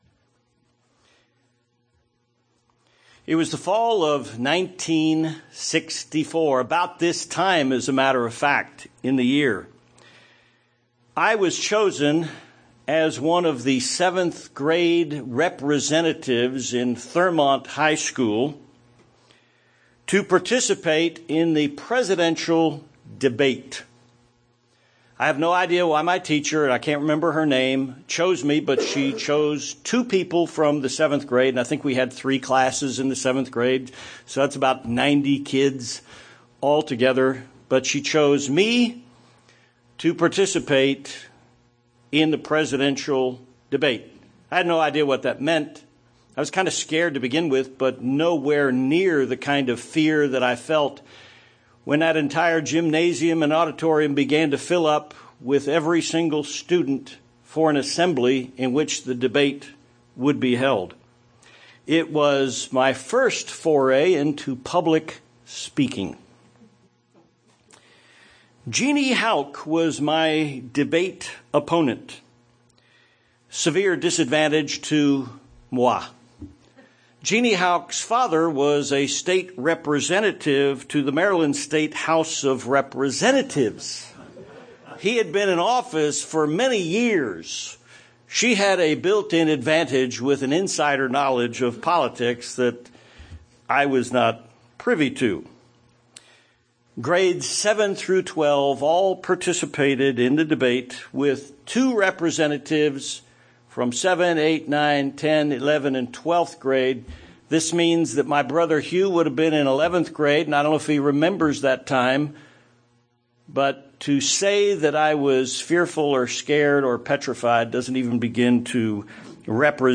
Is it a sin for a Christian to vote in an election? Does God choose all world leaders such as the President of the United States? This sermon looks at the founding of the United States, the role of the Media and individual citizens.